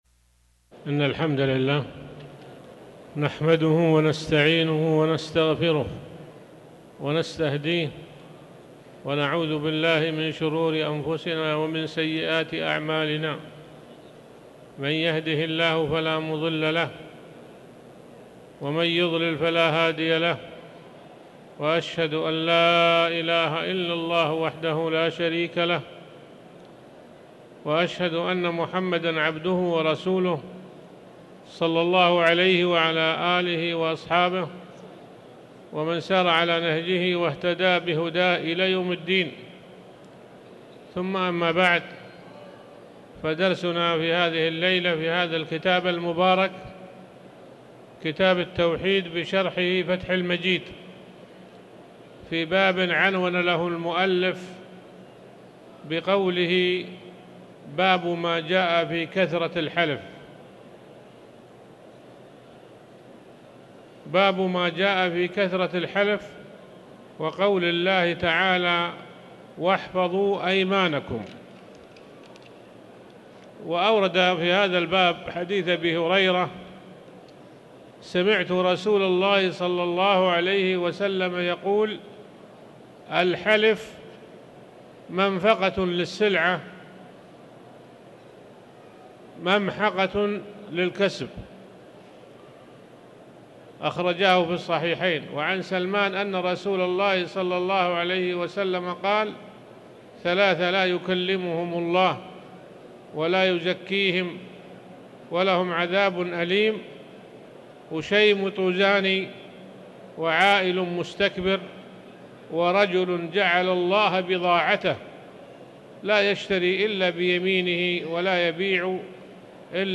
تاريخ النشر ٢ جمادى الأولى ١٤٤٠ هـ المكان: المسجد الحرام الشيخ